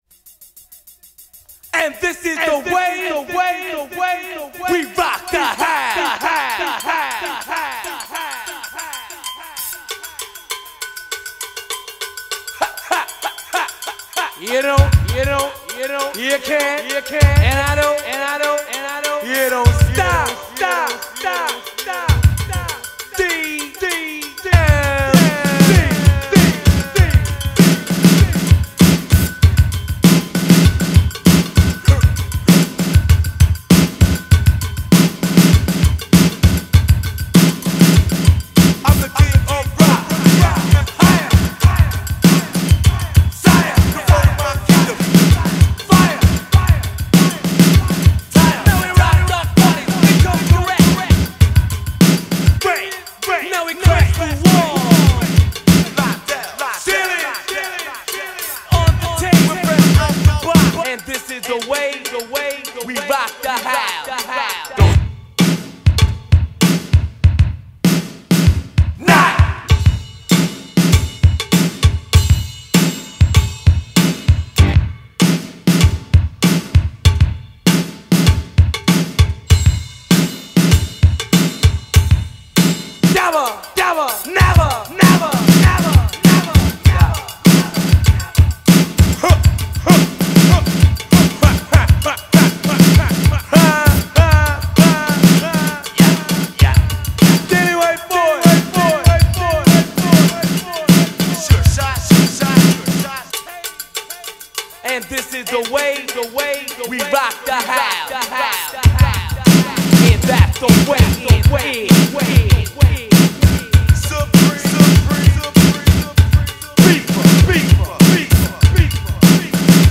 Hip Hop, Old School